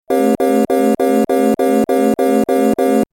دانلود آهنگ هشدار 19 از افکت صوتی اشیاء
جلوه های صوتی
دانلود صدای هشدار 19 از ساعد نیوز با لینک مستقیم و کیفیت بالا